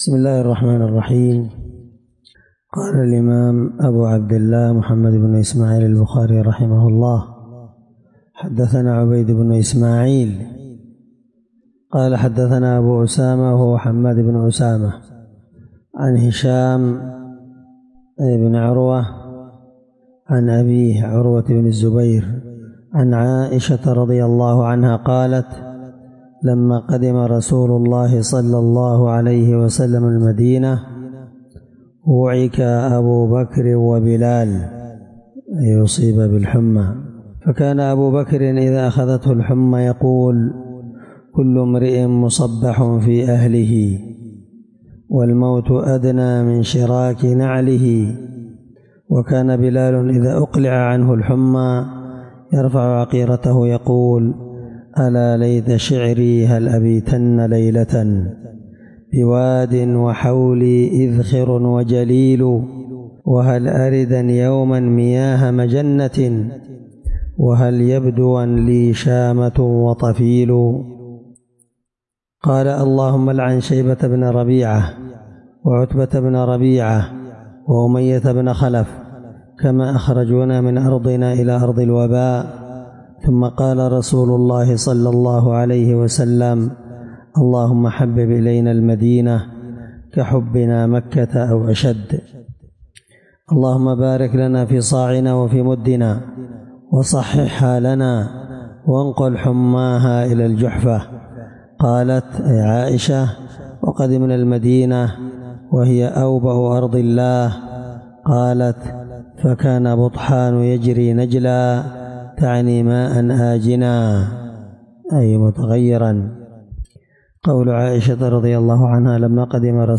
الدرس17من شرح كتاب فضائل المدينة حديث رقم(1889-1890)من صحيح البخاري